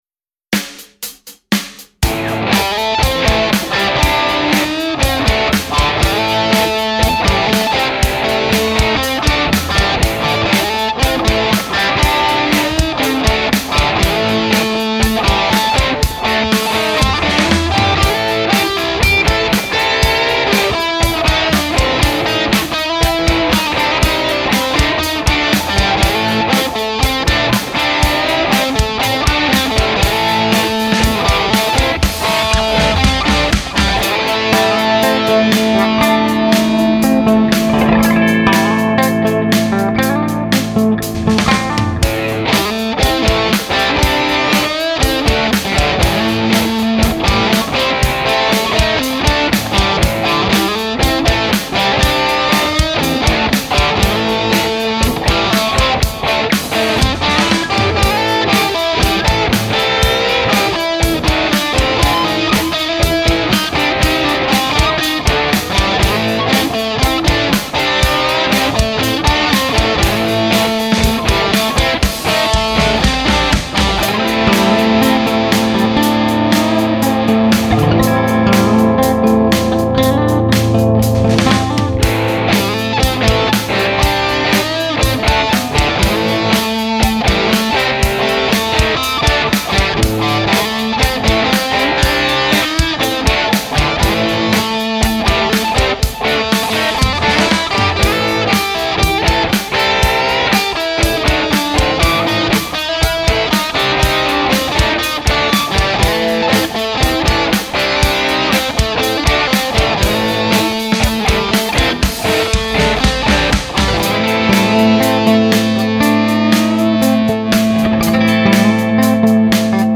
Referenz P-90 Paula : Artec Splendor Tele : P-47|P-47 (Split Coil P-94) Anhänge P-90_VS_P-94_VS_splitCoil.mp3 4,1 MB · Aufrufe: 5.338
Der Unterschied in der Lautstärke ist nicht wirklich groß und kann easy mit dem Saiten-Abstand kompensiert werden. Referenz P-90 Paula : Artec Splendor Tele : P-47|P-47 (Split Coil P-94)